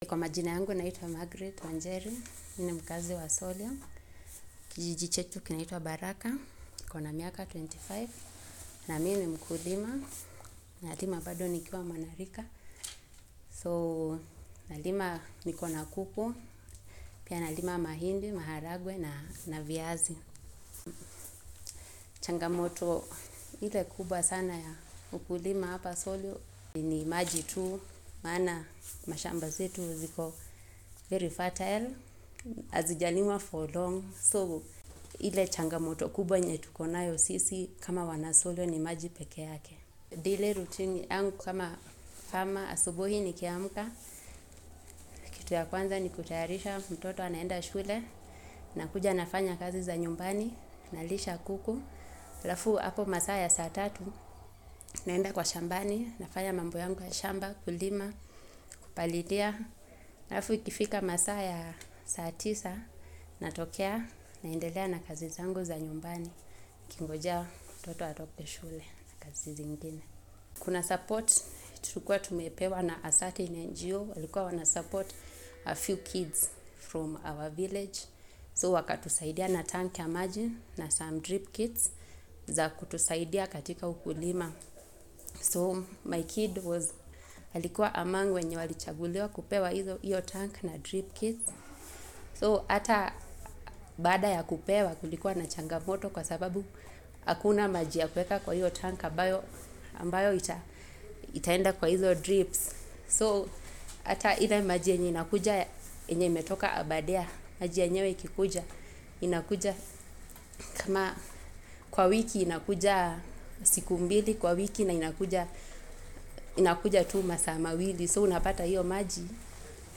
Interview als Audio (nicht übersetzt):